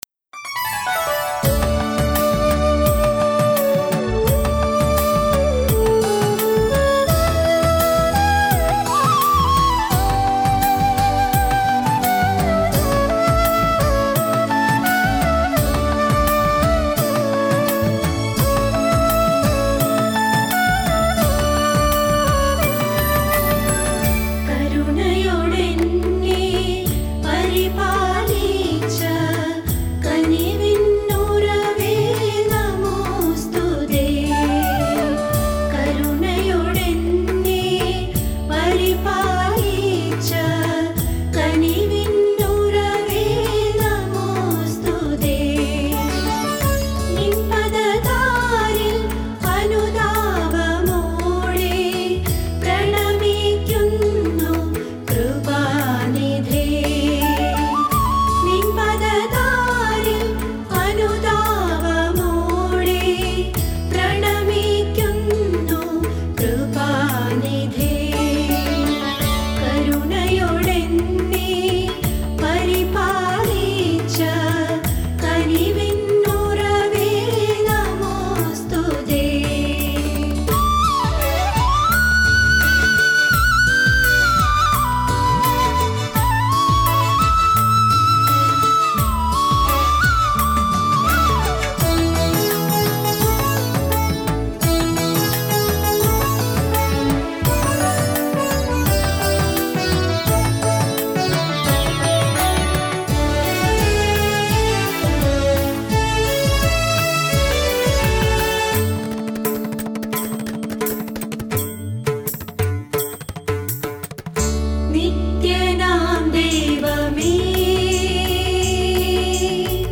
Devotional Songs Jan 2021 Track 9 | St. Thomas Indian Orthodox Church